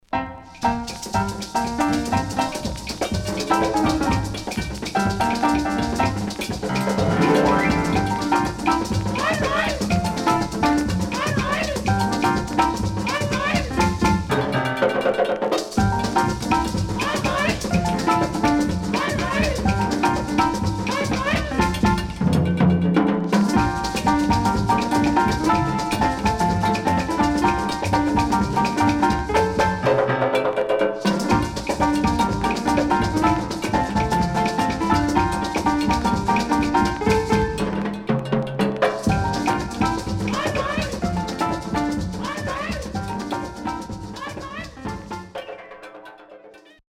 Rare.Jazzyかつ陽気なCarib Musicが堪能できる素晴らしいAlbum
SIDE B:全体的にノイズ入りますが音圧があるので聴けます。